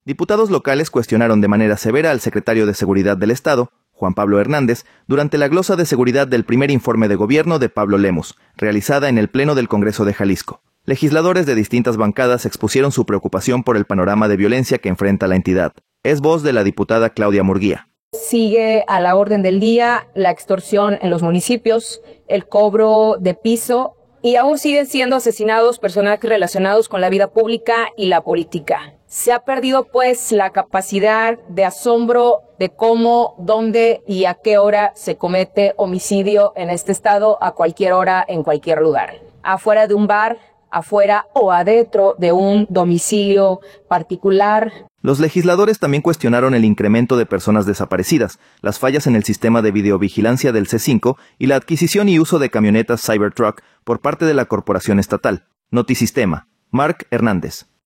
Es voz de la diputada Claudia Murguía.